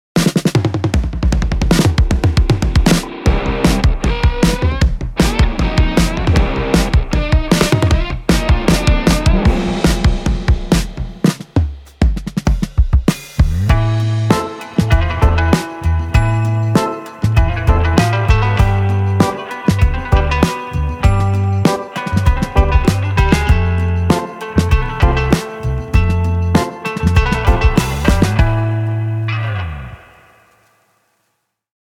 ハードなヒットをもたらす究極のデッド・ドラム
どんなトラックやミックスも、よりハードにヒットするウルトラデッド・ヴィンテージ・ドラムが魂を吹き込む